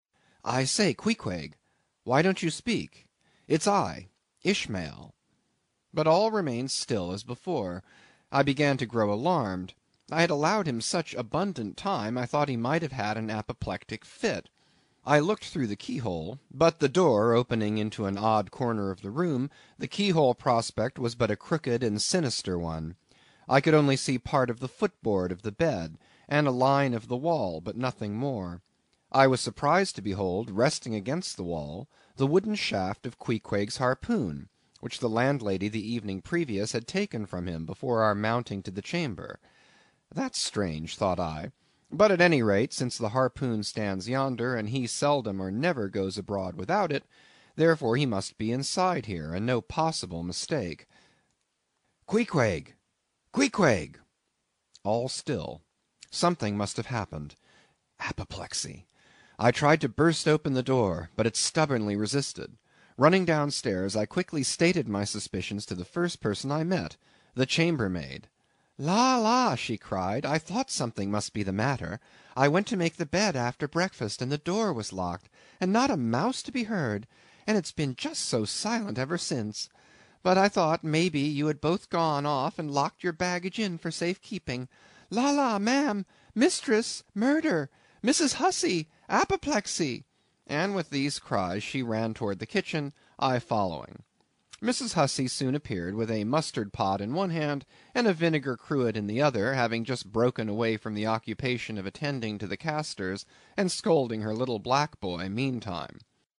英语听书《白鲸记》第300期 听力文件下载—在线英语听力室